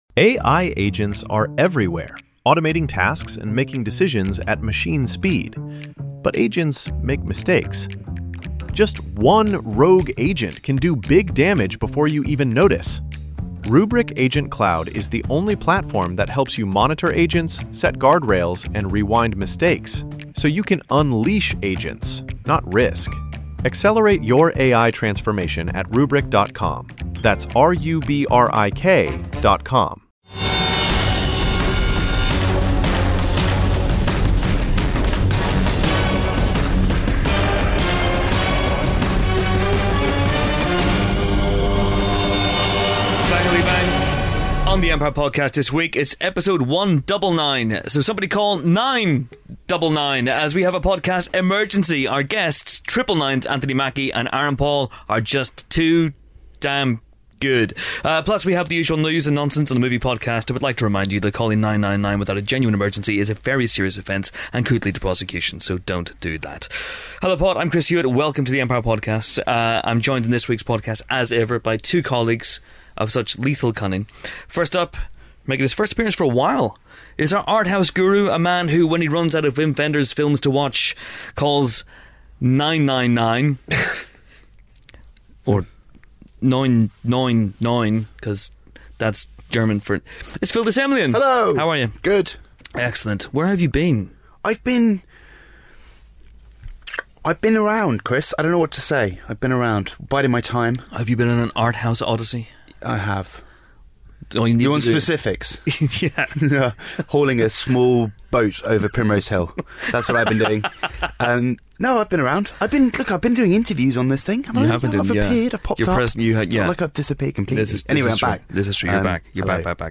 Aaron Paul and Anthony Mackie, stars of new thriller Triple 9, join us in the pod booth for a particularly gritty and macho interview. Plus, we discuss the Empire Awards nominations.